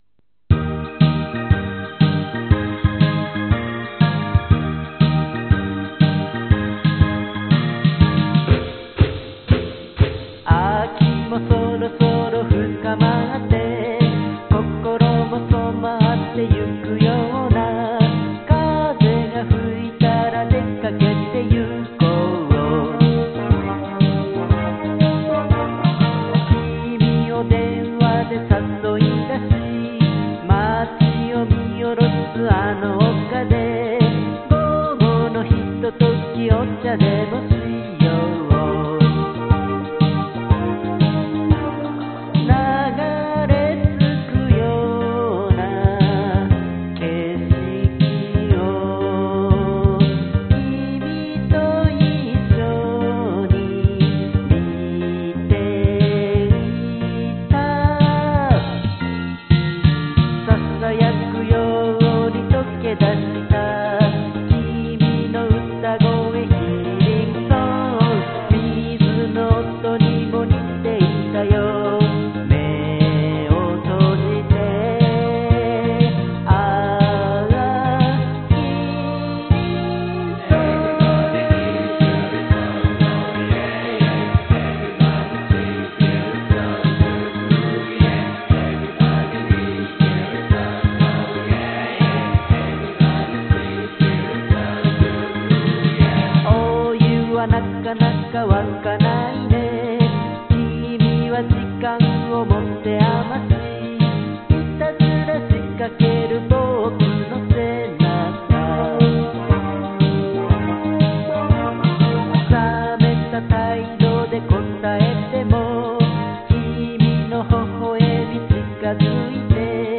歌は下手です。
今年（2004年）録音し直しました。
明るいテンポ感のある曲です。